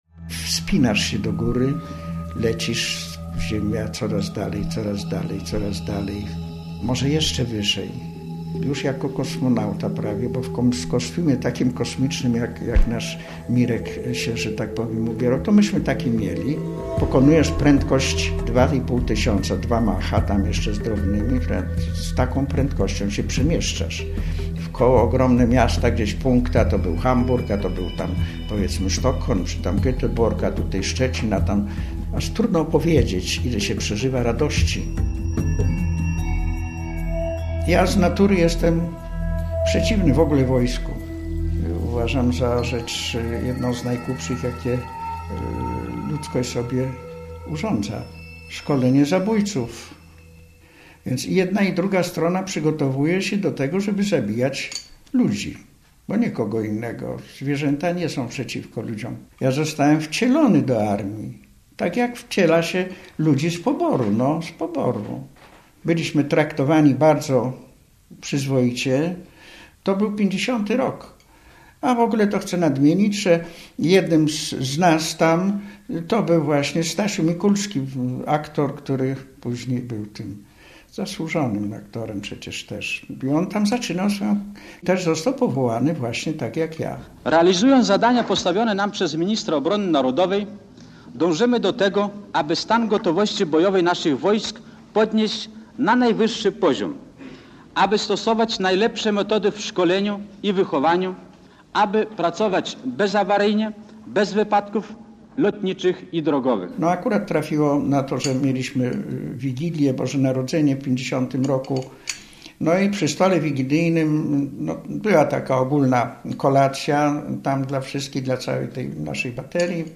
Z tej perspektywy - reportaż
l024o0z6wgcrtfe_reportaz_z_tej_perspektywy.mp3